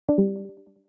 Discord Leave Sound Effect Free Download